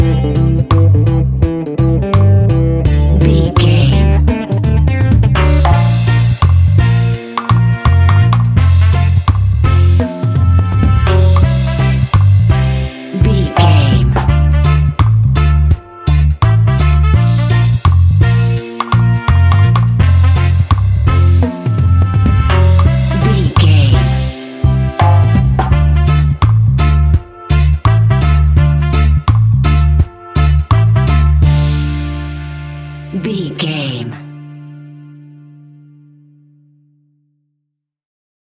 Classic reggae music with that skank bounce reggae feeling.
Aeolian/Minor
laid back
chilled
off beat
drums
skank guitar
hammond organ
percussion
horns